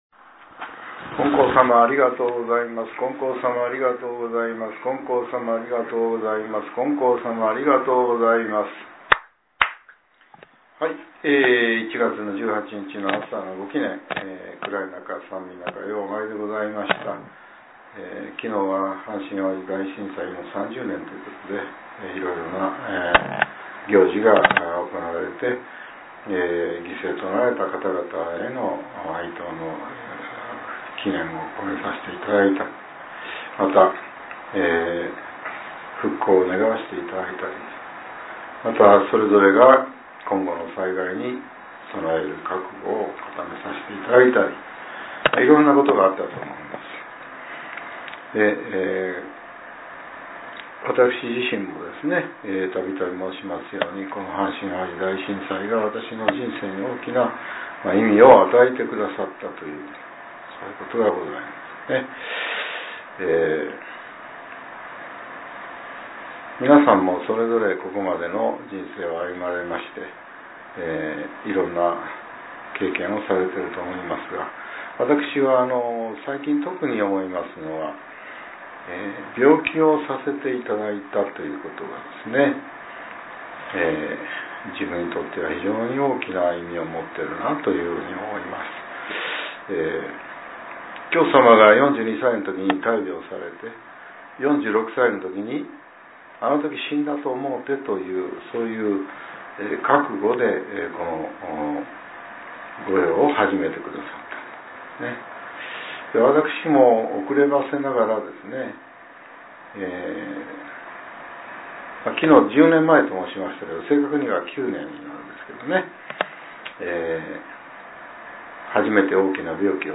令和７年１月１８日（朝）のお話が、音声ブログとして更新されています。